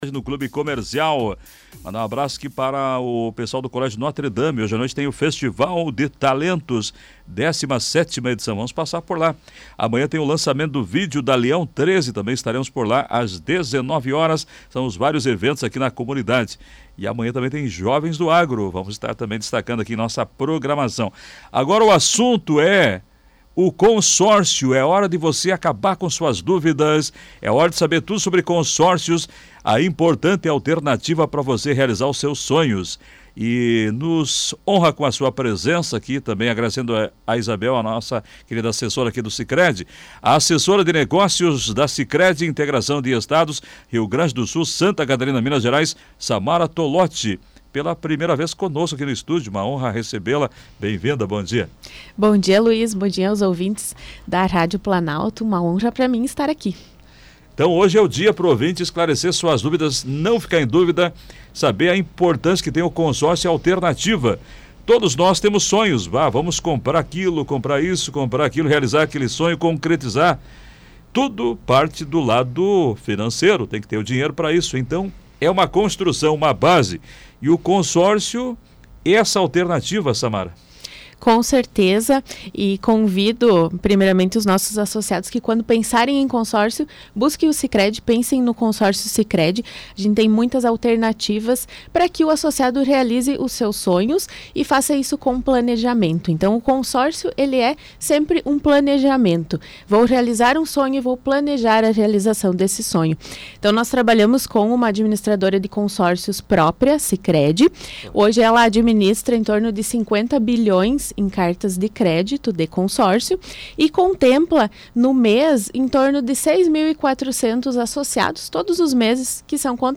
Ela concedeu entrevista